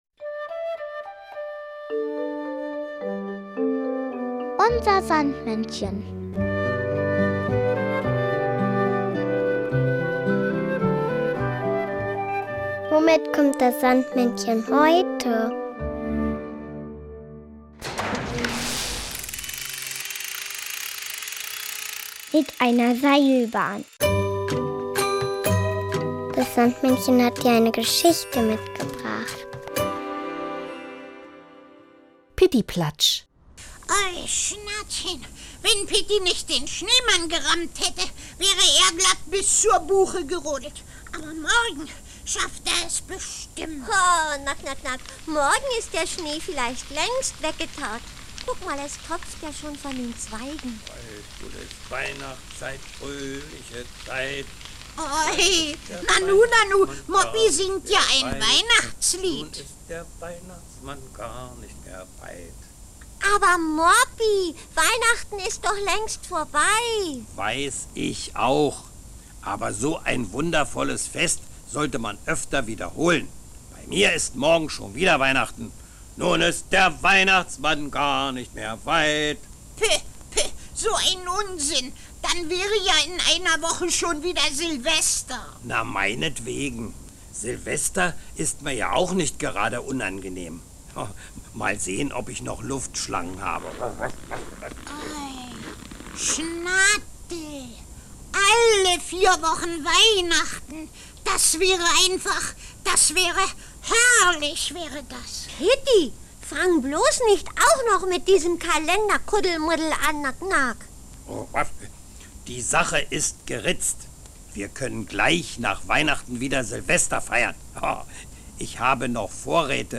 UNSER SANDMÄNNCHEN bringt die Geschichten der beliebten Sandmannserien zum Hören mit.